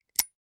Unlock Door.wav